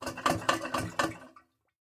Звуки унитаза
Звук сломанного сливного бачка при нажатии кнопки